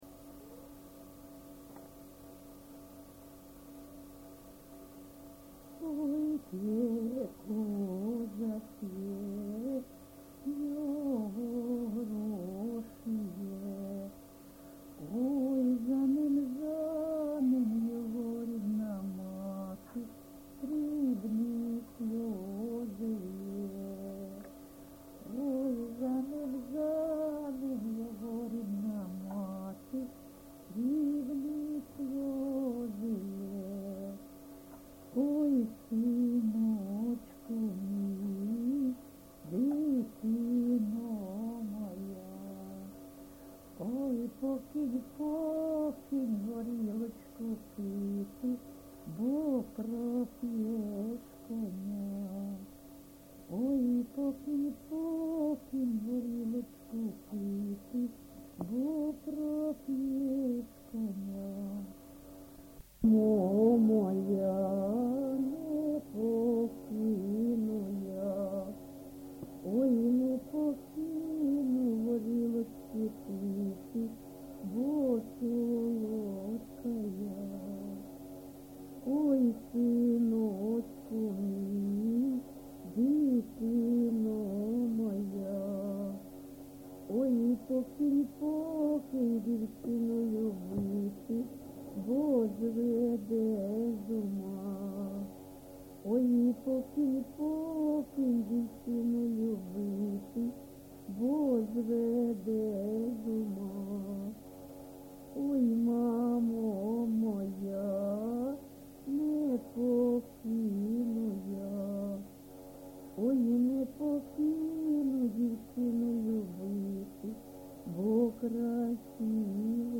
ЖанрПісні з особистого та родинного життя, Пʼяницькі
Місце записум. Бахмут, Бахмутський район, Донецька обл., Україна, Слобожанщина